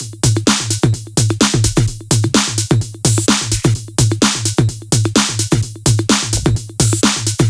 VFH1 128BPM Northwood Kit 2.wav